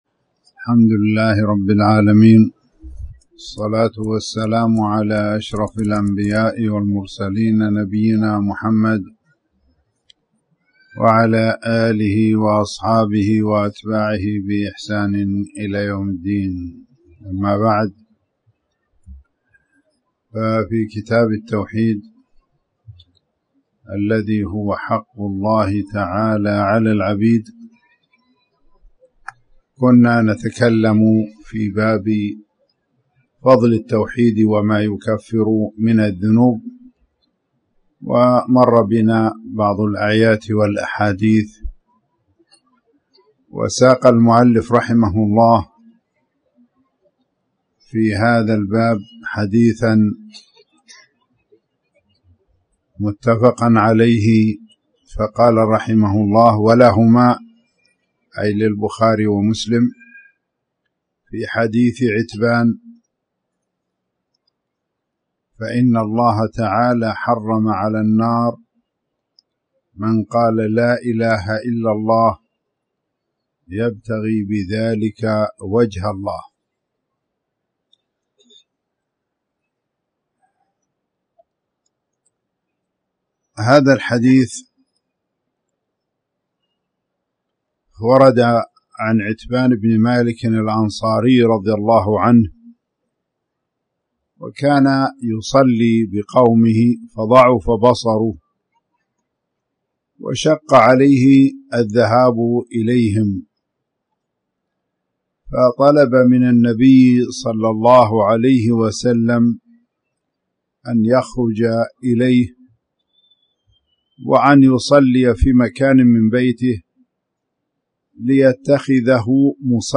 تاريخ النشر ١ ربيع الأول ١٤٣٩ هـ المكان: المسجد الحرام الشيخ